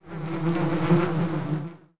flies1.wav